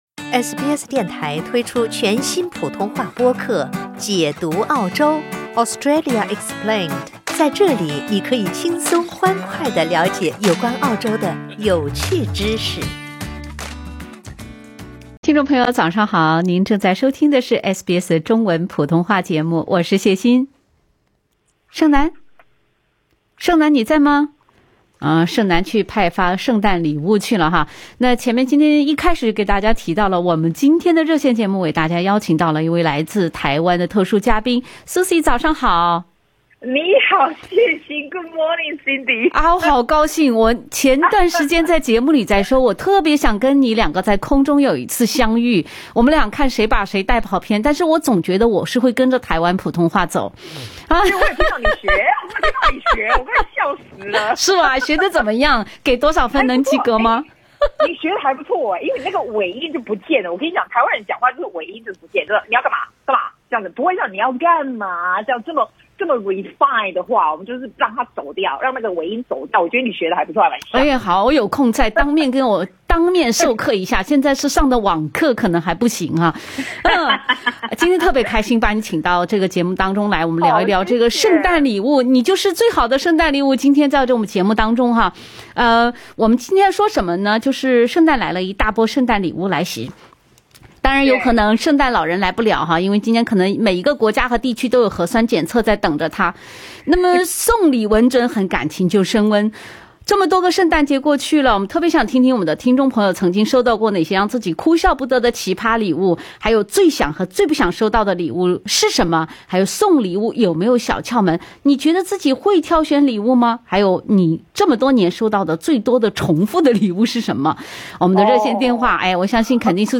从台湾腔的普通话到北京味儿的普通话，再到广东普通话、上海普通话。
还有听众拨打电话想要对某些主持人表达问候，却走窜了场，被主持人笑称：要感谢这位听众让SBS成功笑到了最后。